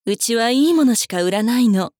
大人女性│女魔導師│店番ボイス